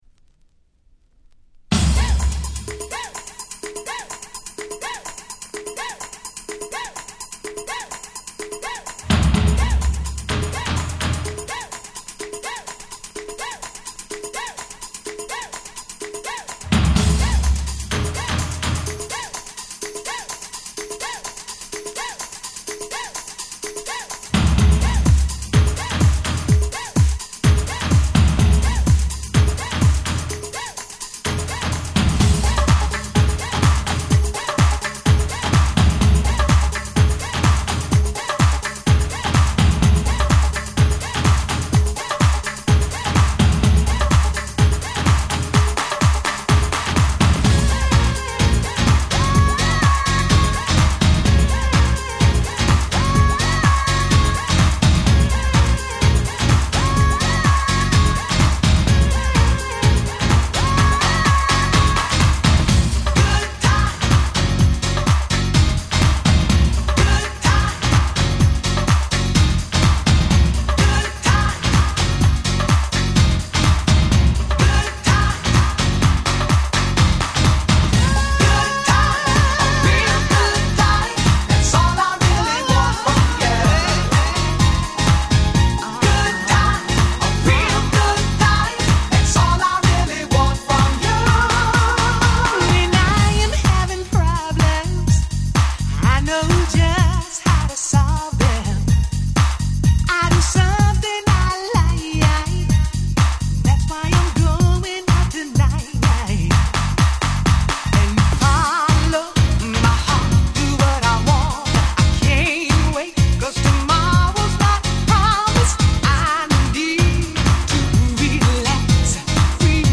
その音と来たらまさに当時で言えば『現代版New Jack Swing』と言った感じでした。